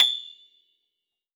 53q-pno25-A5.wav